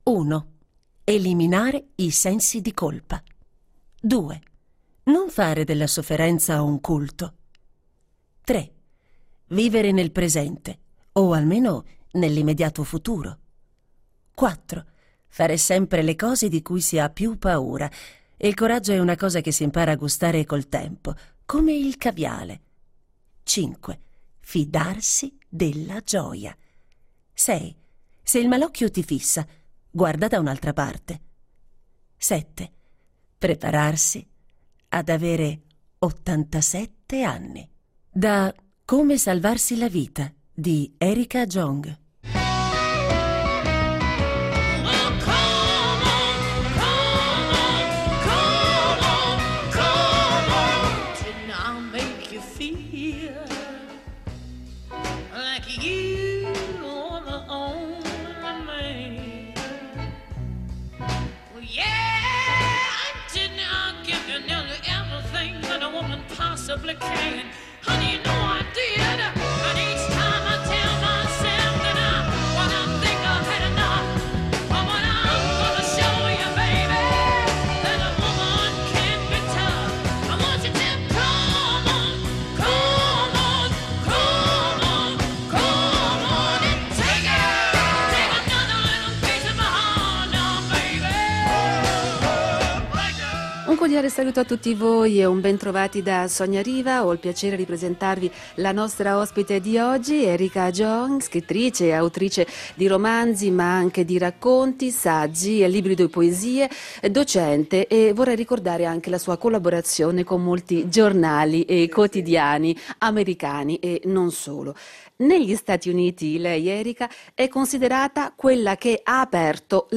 Oltre le frontiere del sesso - Incontro con la scrittrice Erica Jong